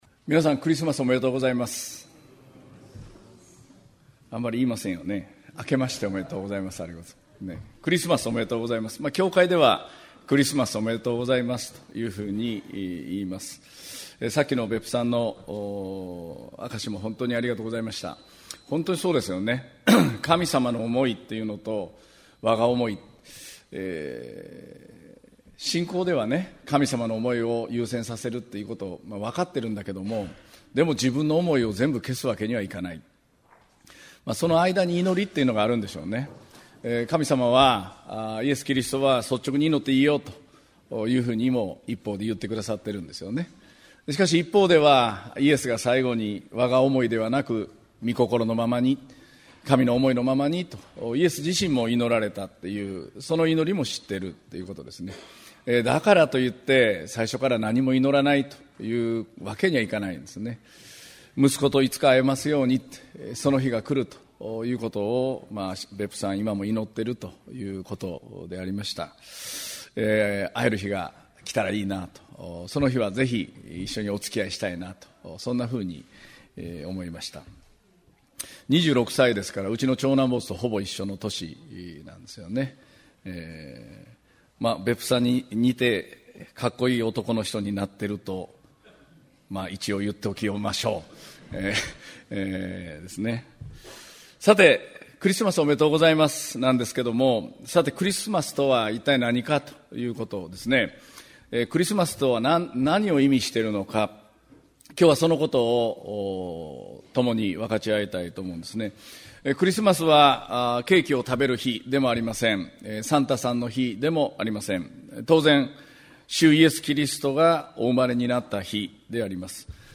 2018年12月23日（日）クリスマス主日礼拝 宣教題「クリスマス―人間に戻る日」 | 東八幡キリスト教会